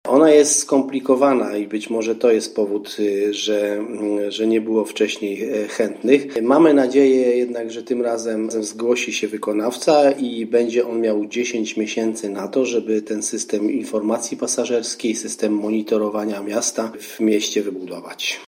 Brak chętnych do wykonania tej inwestycji wynika być może z tego, że nie należy ona do łatwych – ocenia rzecznik.